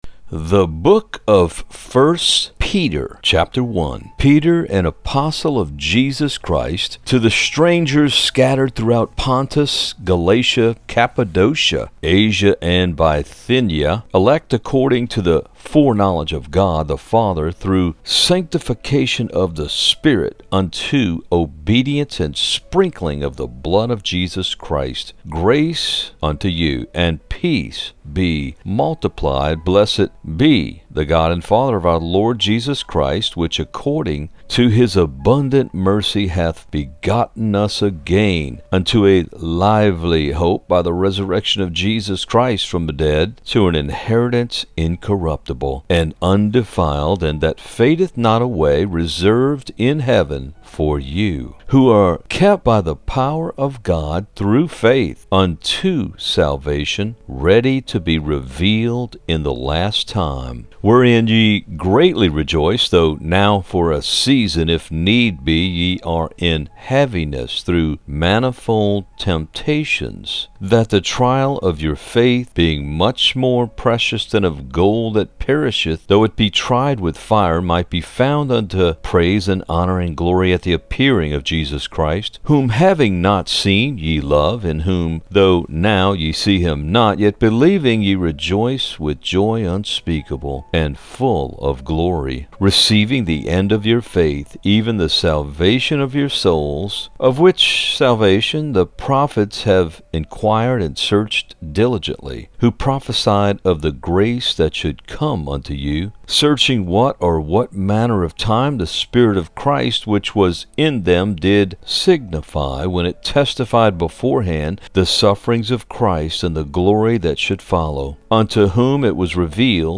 THE BOOK OF 1 PETER NARRATED [PODCAST] - SafeGuardYourSoul
book-of-1-peter-narrated-EDITED-MUSIC.mp3